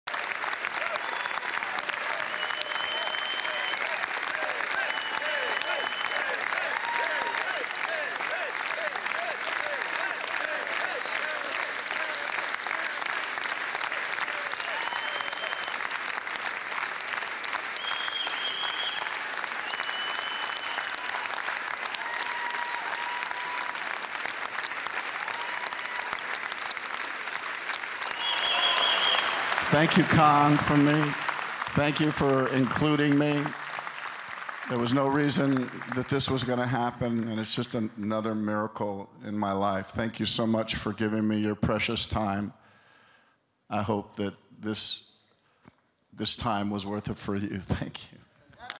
Une standing ovation si émouvante